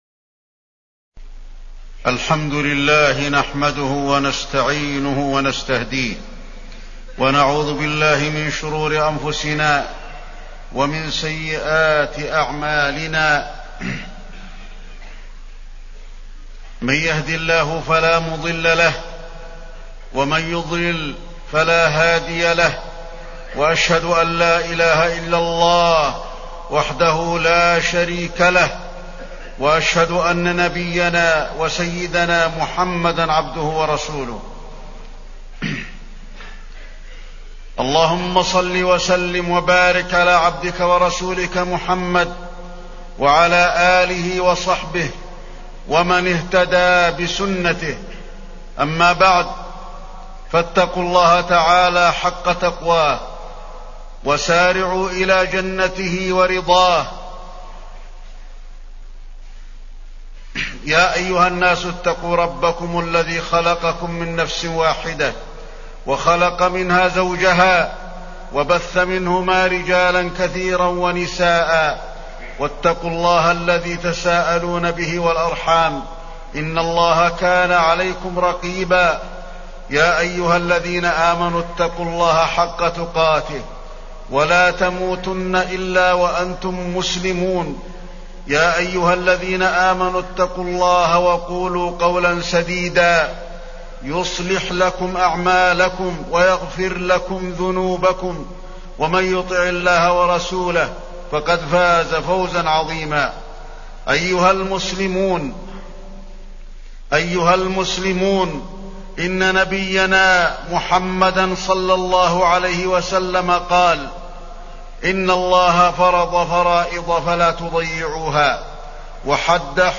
تاريخ النشر ٩ محرم ١٤٢٩ هـ المكان: المسجد النبوي الشيخ: فضيلة الشيخ د. علي بن عبدالرحمن الحذيفي فضيلة الشيخ د. علي بن عبدالرحمن الحذيفي الأمر بالمعروف والنهي عن المنكر